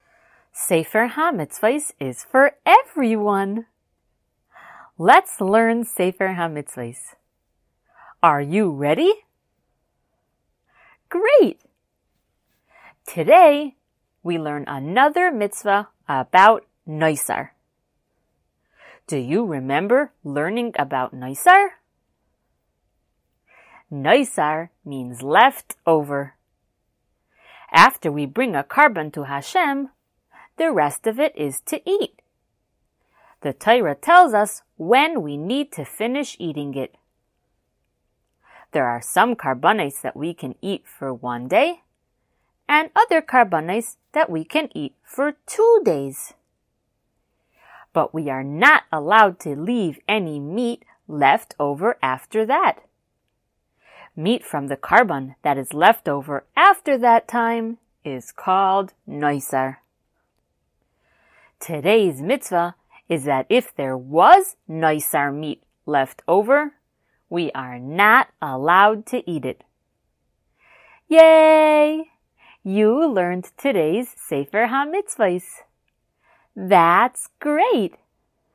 Color Shiur #171!
SmallChildren_Shiur171.mp3